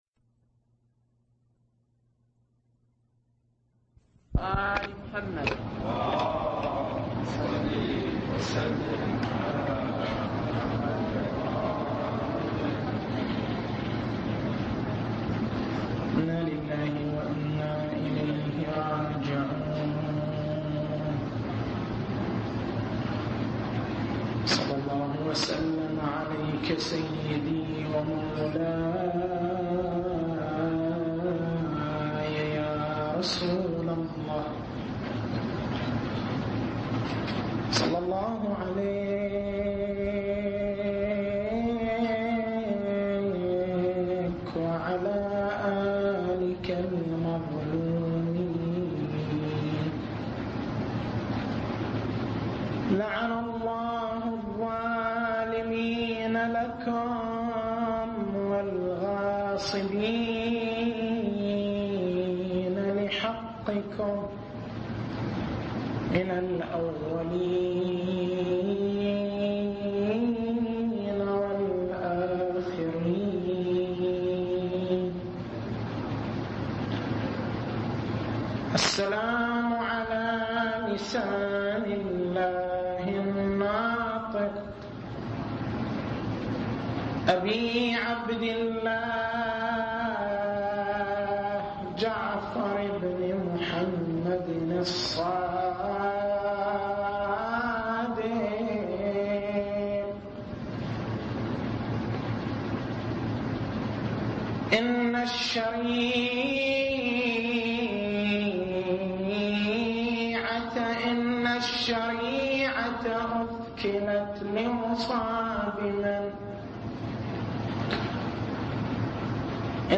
تاريخ المحاضرة: 15/07/1421 التسجيل الصوتي: شبكة الضياء > مكتبة المحاضرات > مناسبات متفرقة > أحزان آل محمّد